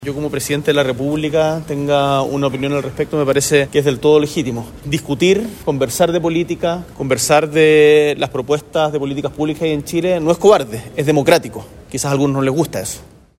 Desde el Palacio de La Moneda, el mandatario aseguró que no está para este tipo de peleas pequeñas, defendiendo su alocución y reiterando que el Ejecutivo siempre velará porque los derechos sociales se mantengan.